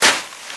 STEPS Sand, Walk 27.wav